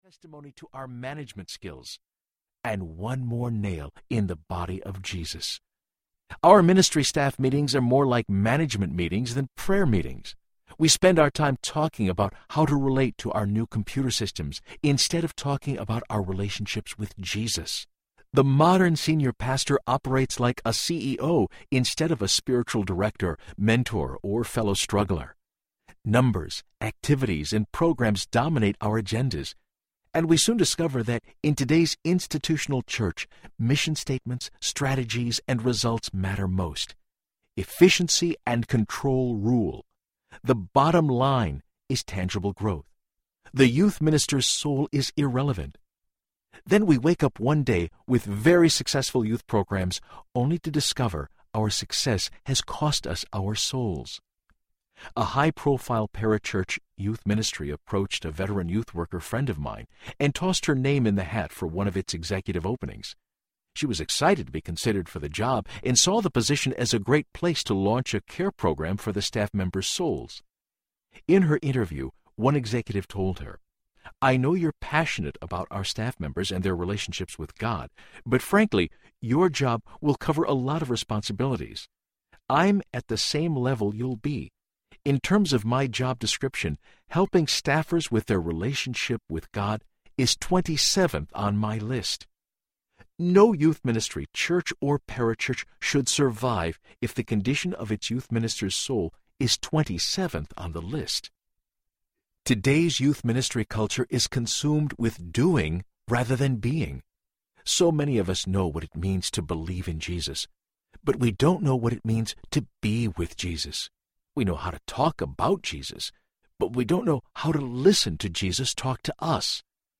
Getting Fired for the Glory Audiobook
Narrator
2.55 Hrs. – Unabridged